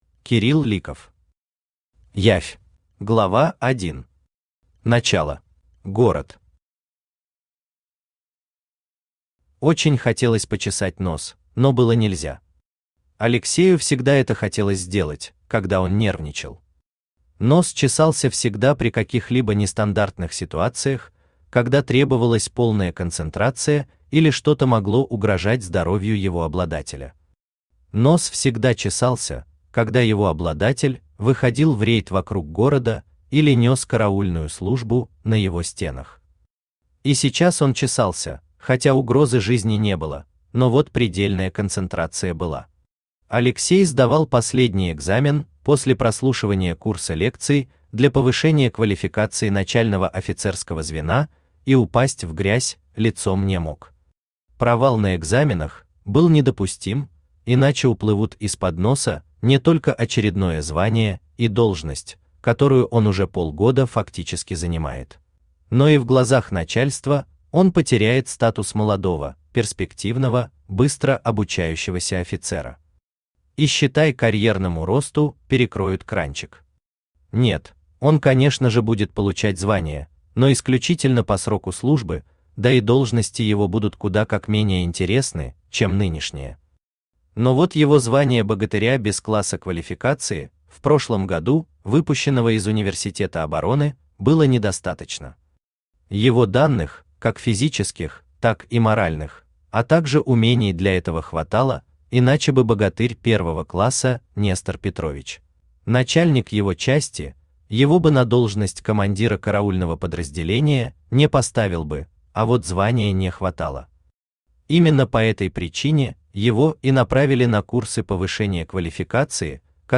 Аудиокнига Явь | Библиотека аудиокниг
Aудиокнига Явь Автор Кирилл Ликов Читает аудиокнигу Авточтец ЛитРес.